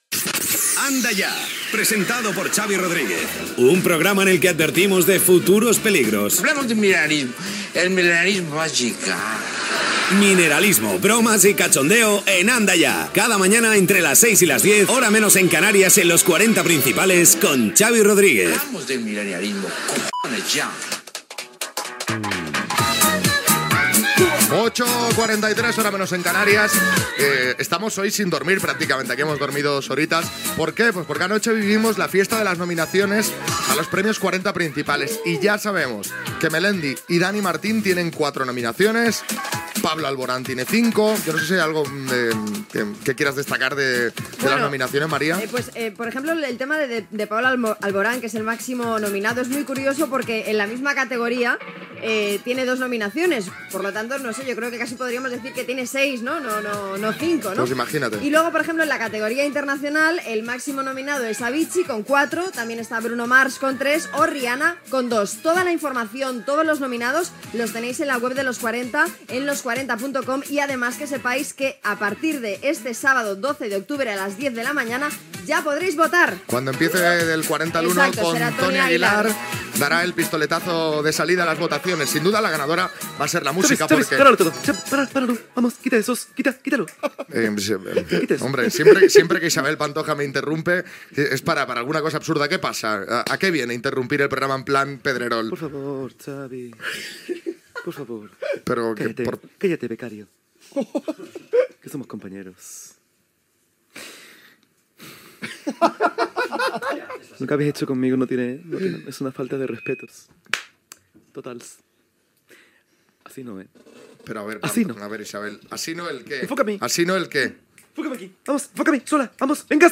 Indicatiu del programa, nominacions als premis 40 Principales. Imitació d'Isabel Pantoja que es queixa de no haver estat nominada i indicatiu
Entreteniment